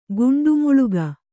♪ guṇḍu muḷuga